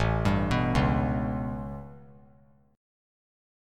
A9 Chord
Listen to A9 strummed